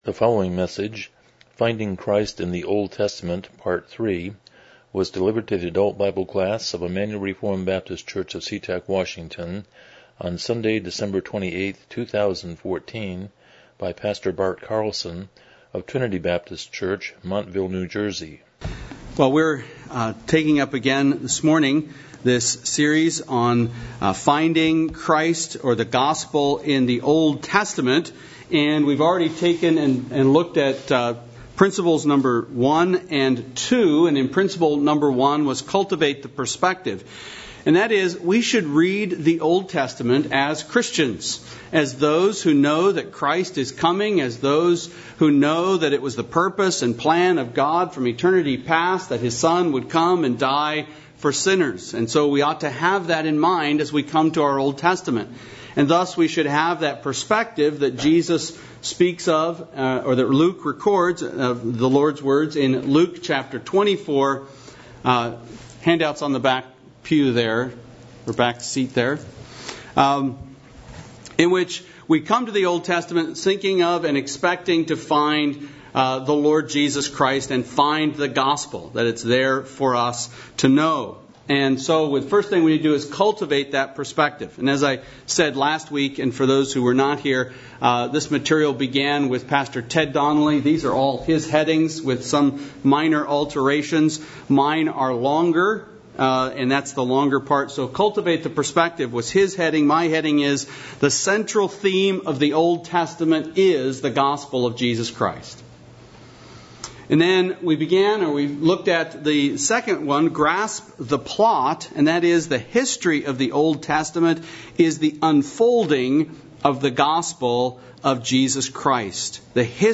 Miscellaneous Service Type: Sunday School « Why is there anything to celebrate at Christmas?